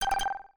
Riddle Block Solved Sound Effect
riddle-block-solved.mp3